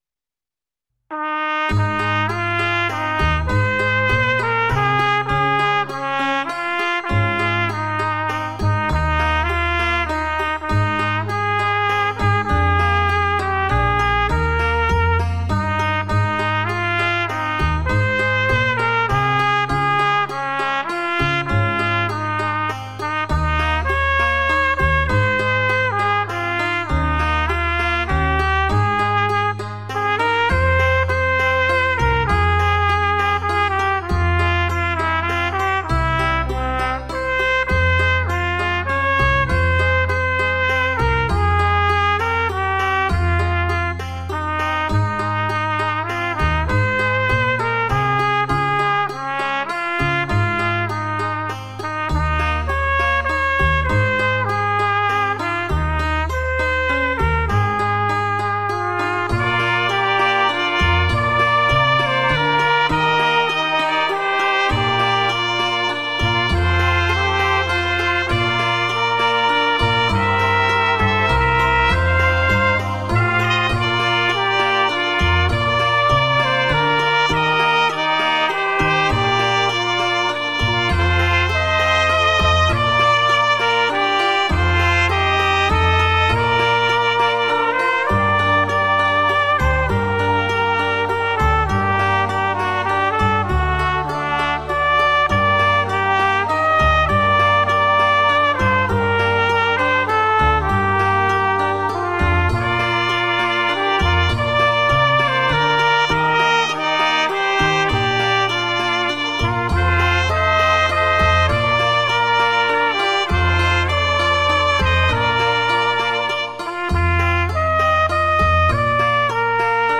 Delightful nostalgic melodies for cornet solo.